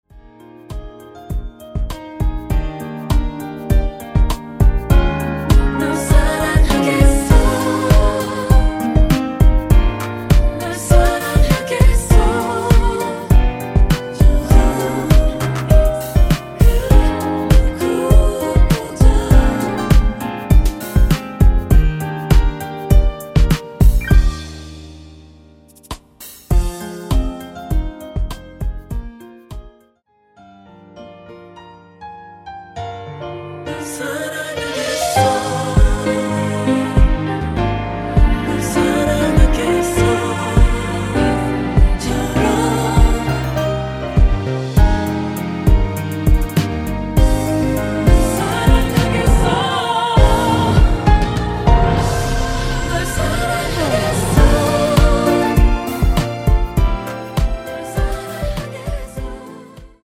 코러스 포함된 버젼 입니다.(미리듣기 참조 하세요)
Eb
앞부분30초, 뒷부분30초씩 편집해서 올려 드리고 있습니다.
중간에 음이 끈어지고 다시 나오는 이유는